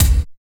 87 GAT KIK-R.wav